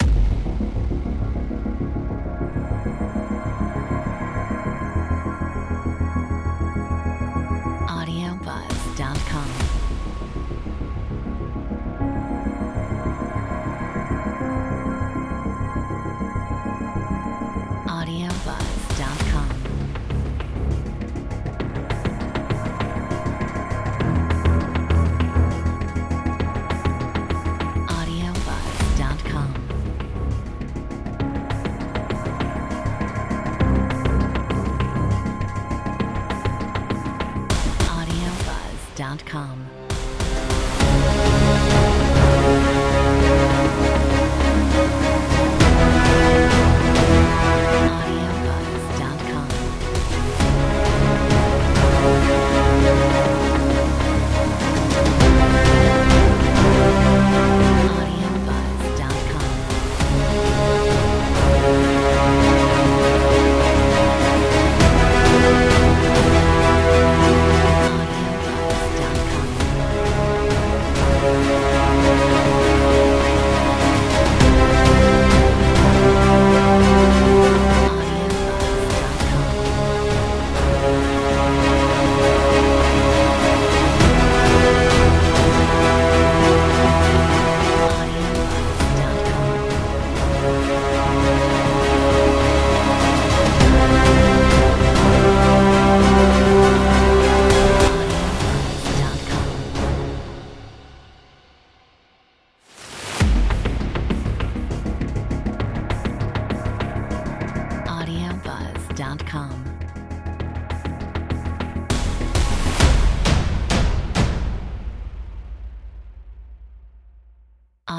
Metronome 100